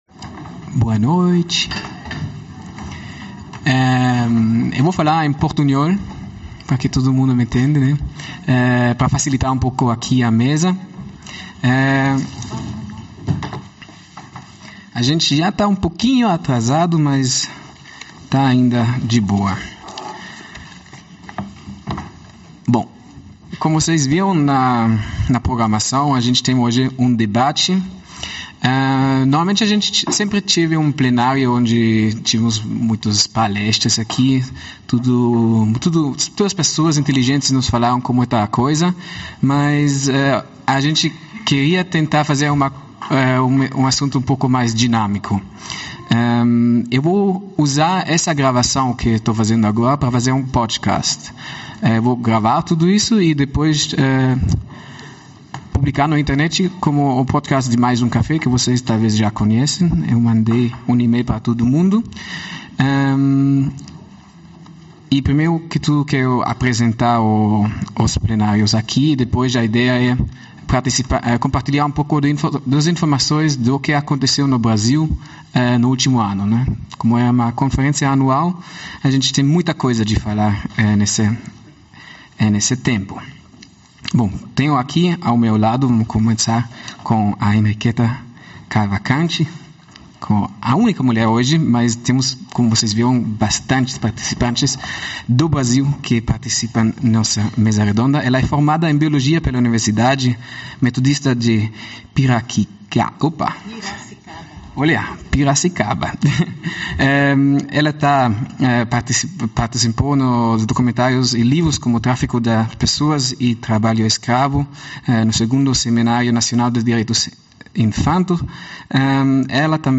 Discussão:
Audio-Gravação da debate | Download (mp3)